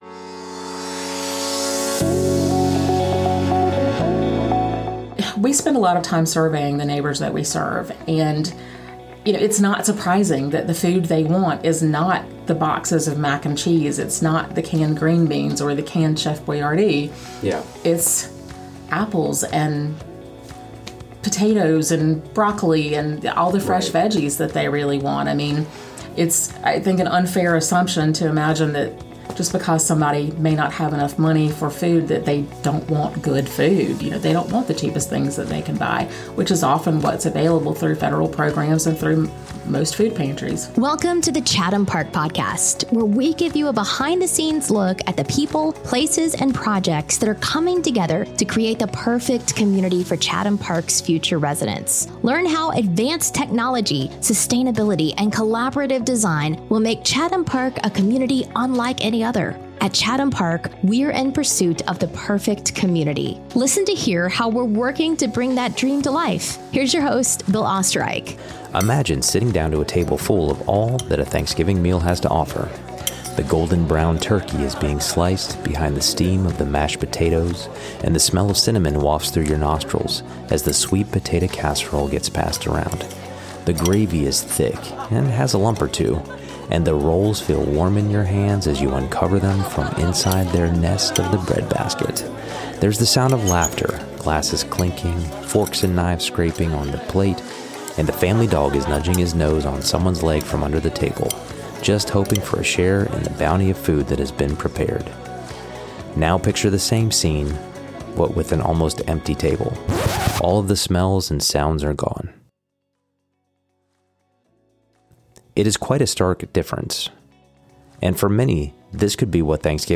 Listen to the interview and learn a little bit about what we do across Chatham County.